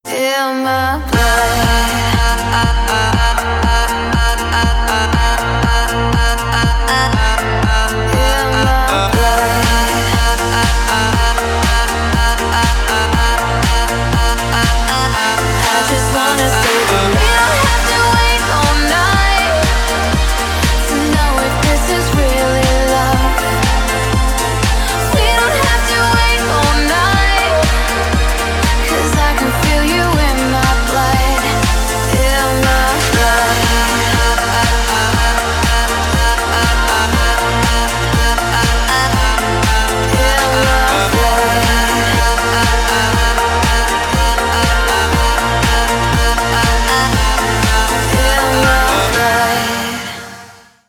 • Качество: 320, Stereo
dance
club